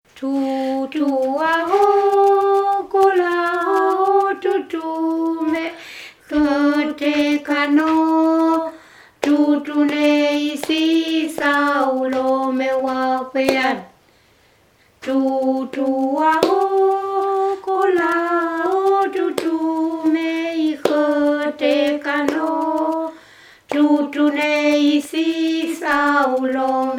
Lifou
Chants enfantins kanaks
Pièce musicale inédite